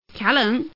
Index of /mahjong_paohuzi_Common_test1/update/1575/res/sfx/youxian/woman/